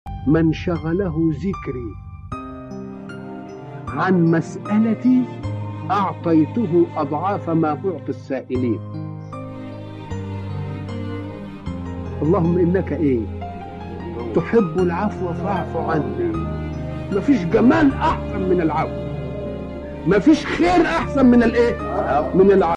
الراجل دا صوته نفسه غريب مريح جدا الله يرحمك ي شيخنا الجليل الجميل